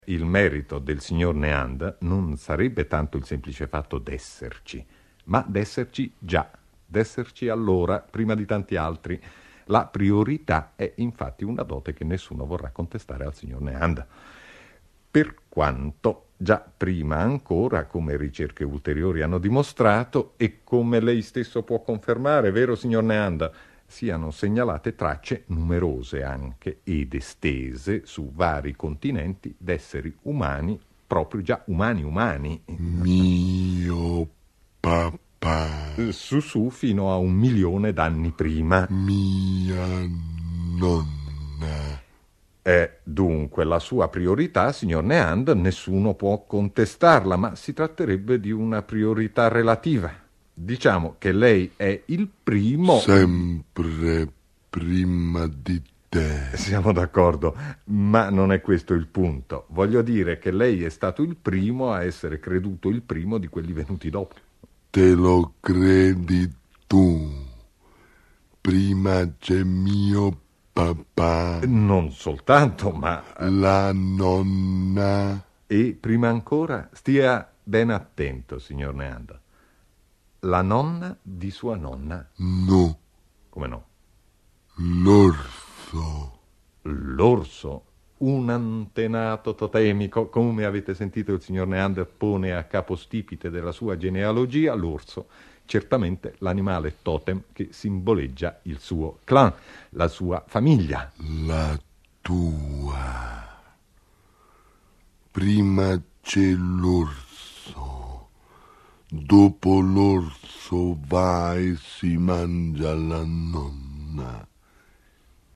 Dalle interviste impossibili: Italo Calvino intervista l'Uomo di Neanderthal